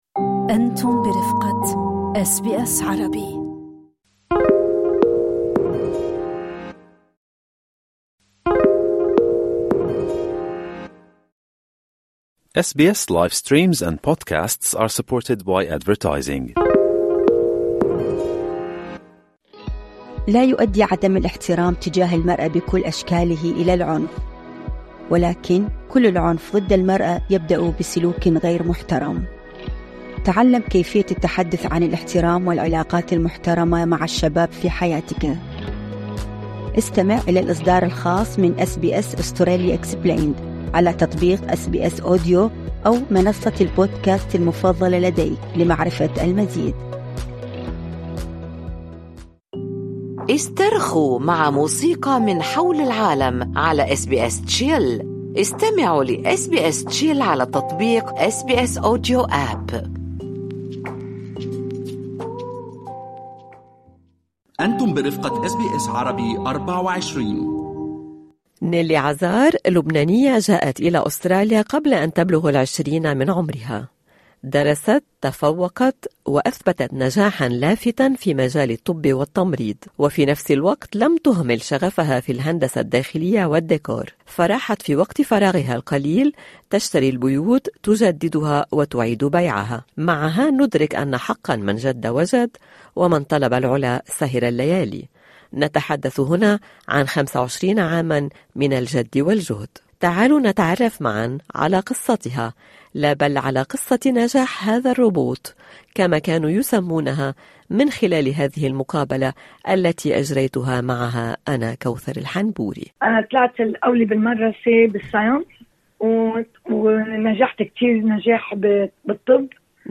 استمعوا إلى اللقاء كاملا في المدونة الصوتية في أعلى الصفحة.